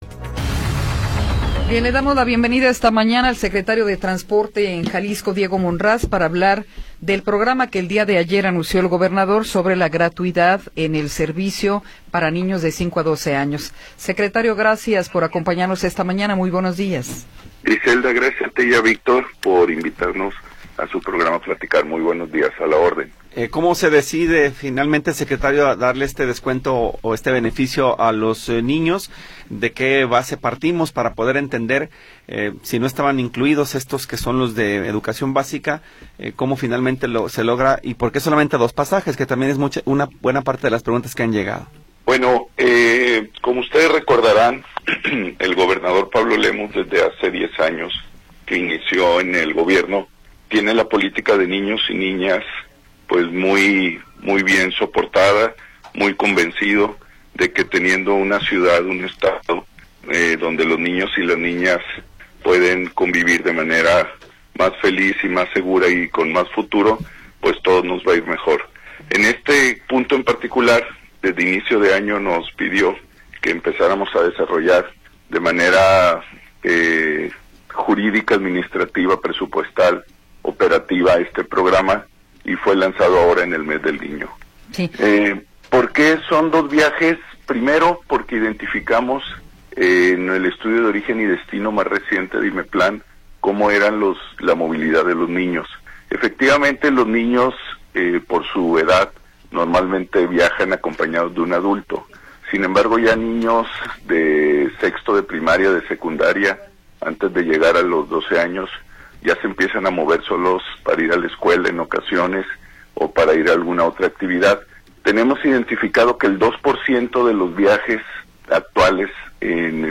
Entrevista con Diego Monraz Villaseñor